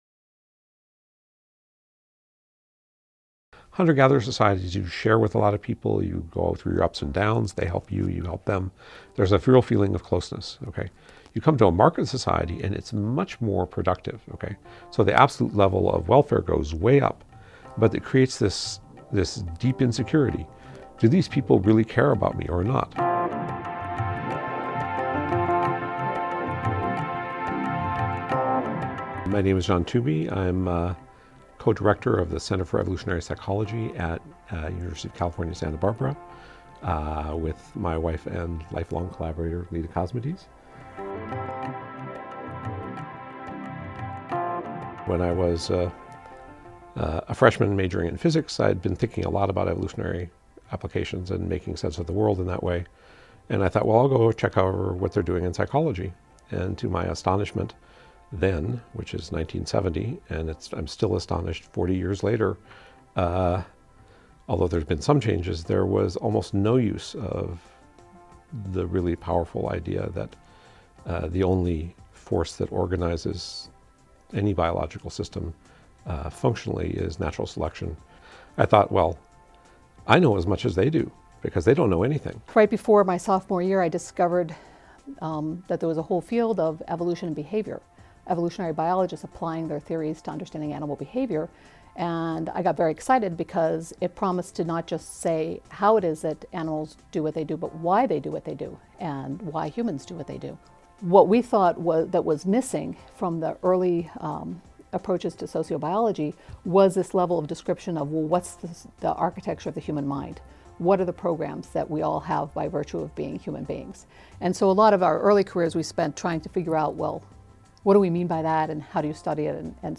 Stone Age Minds: A conversation with evolutionary psychologists Leda Cosmides and John Tooby